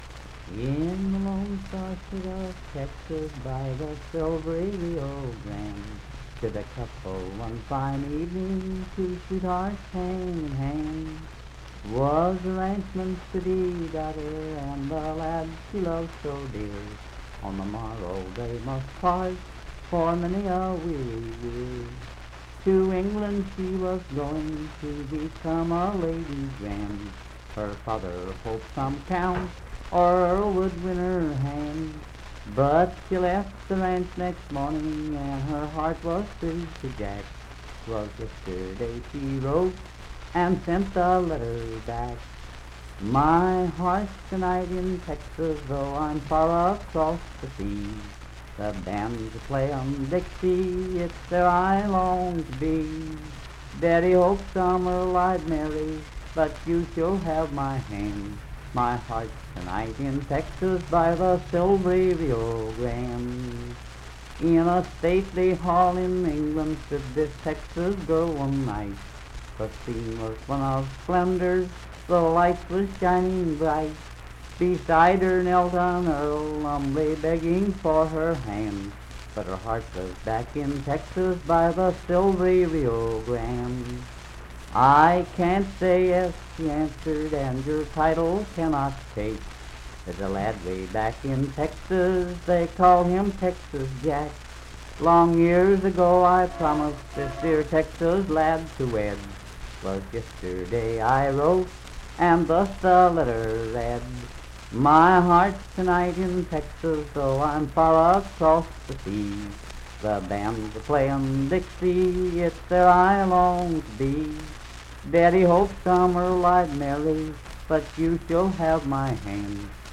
Unaccompanied vocal music
Voice (sung)
Clay County (W. Va.), Clay (W. Va.)